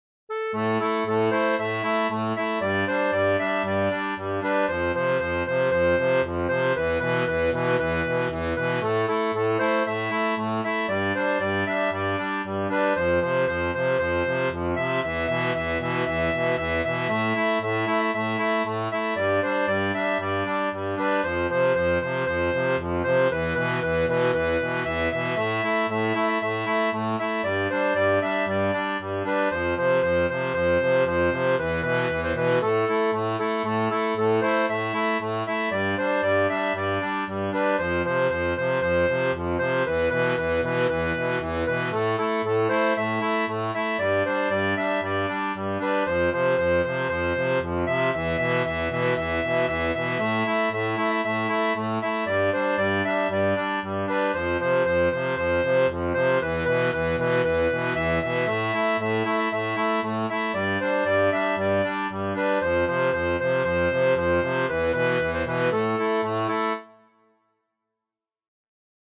• Une tablature pour diato 2 rangs transposée en La
Chant de marins